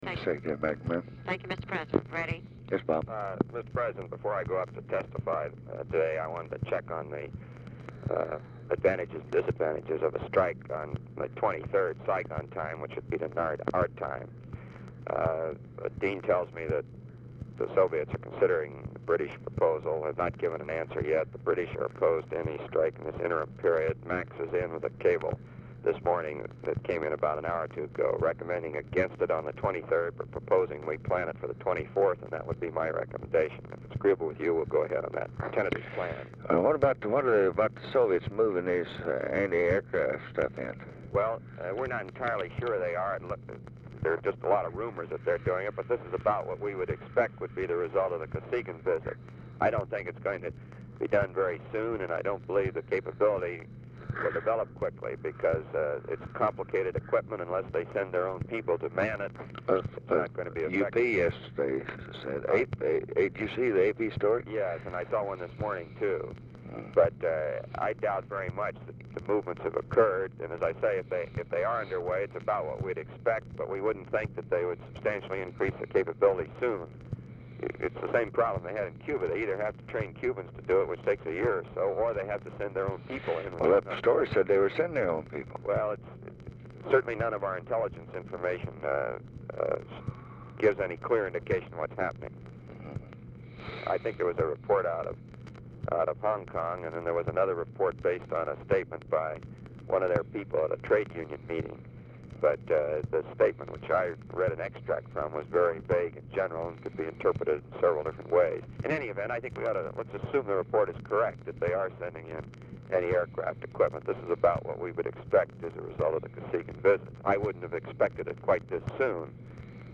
Telephone conversation # 6868, sound recording, LBJ and ROBERT MCNAMARA, 2/22/1965, 8:48AM
Format Dictation belt
Location Of Speaker 1 Mansion, White House, Washington, DC